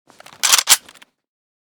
sks_unjam.ogg.bak